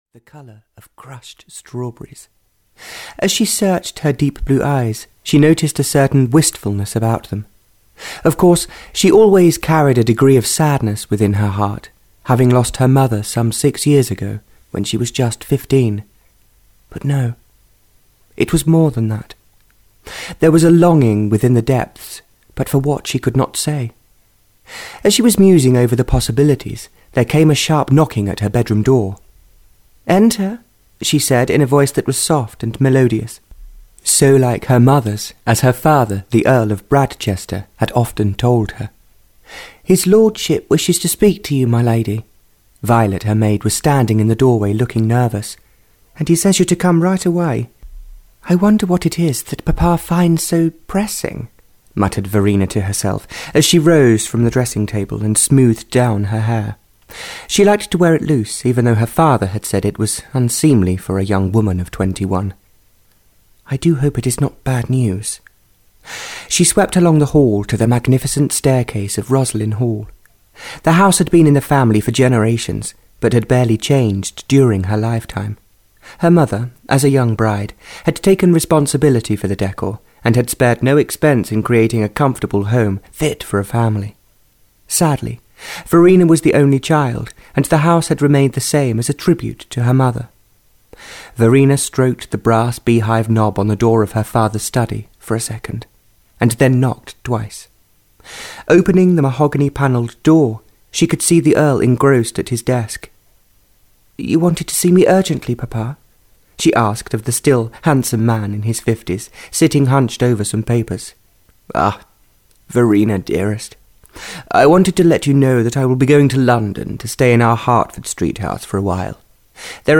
Audio knihaThe Heart Of Love (Barbara Cartland’s Pink Collection 30) (EN)
Ukázka z knihy